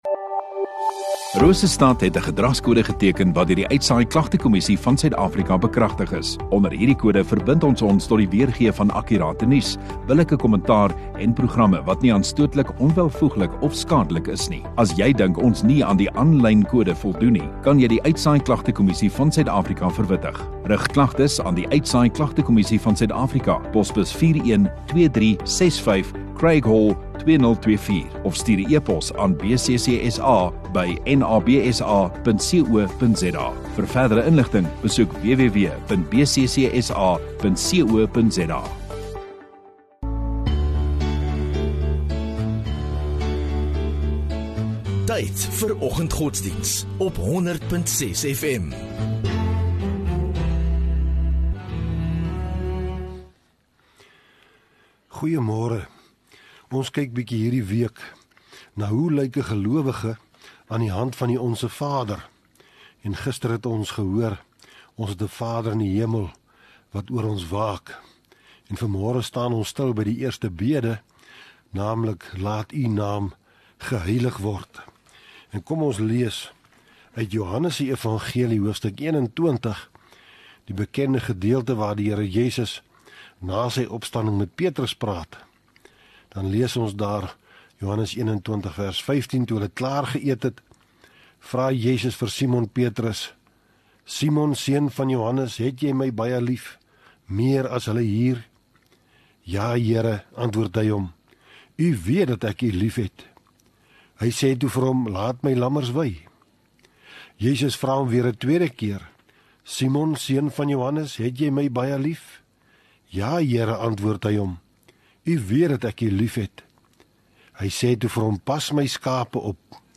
23 Sep Dinsdag Oggenddiens